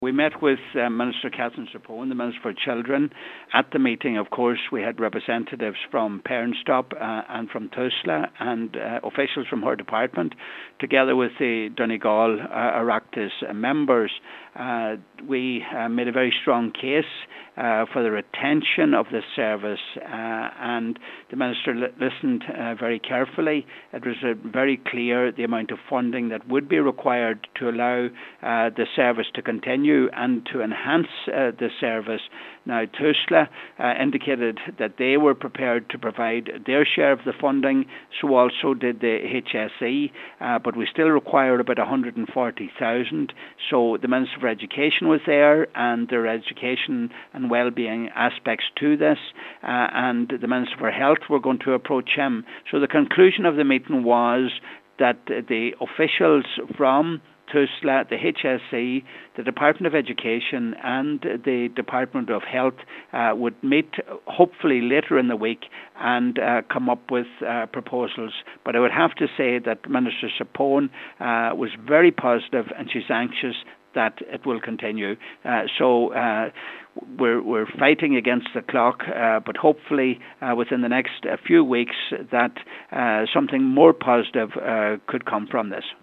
Donegal Deputy Pat the Cope Gallagher says Minister Zappone appeared committed to resolving the funding issue: